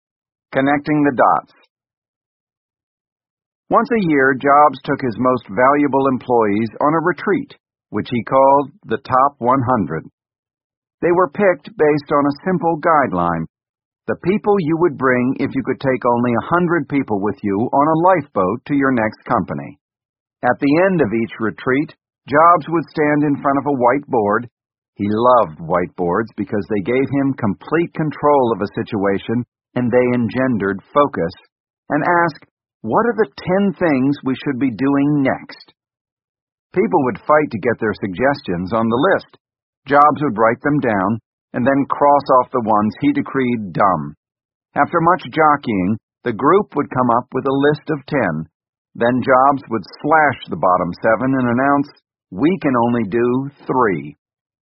本栏目纯正的英语发音，以及完整的传记内容，详细描述了乔布斯的一生，是学习英语的必备材料。